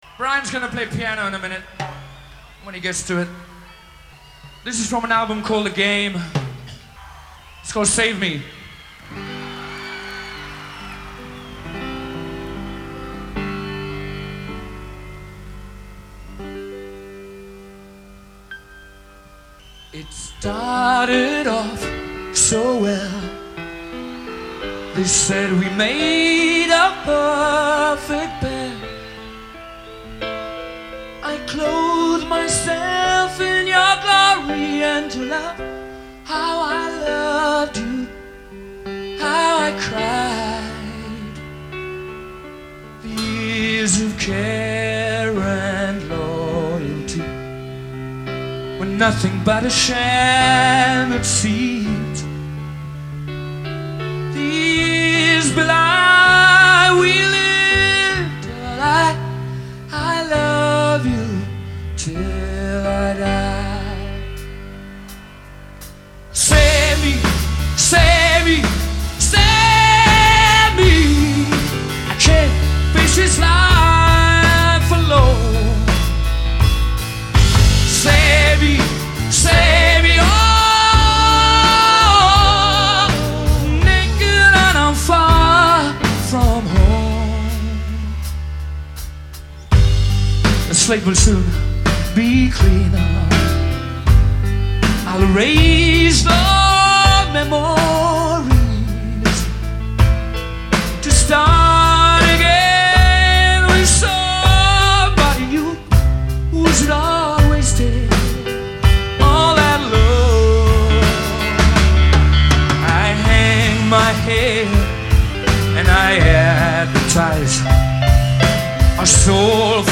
live in montreal